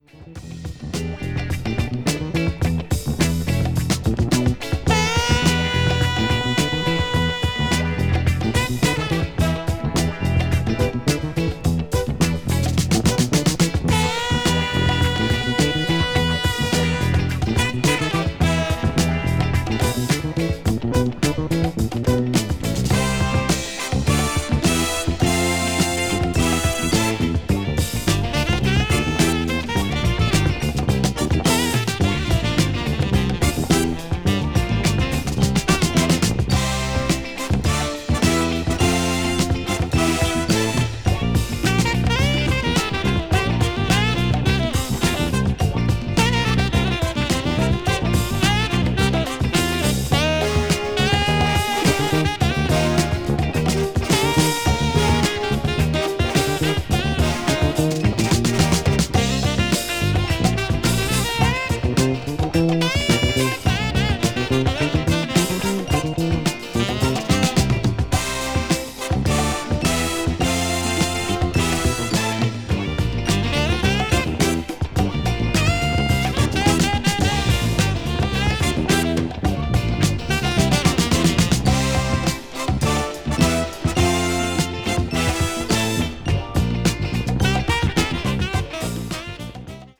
jazz funk   jazz groove   r&b   soul   soul jazz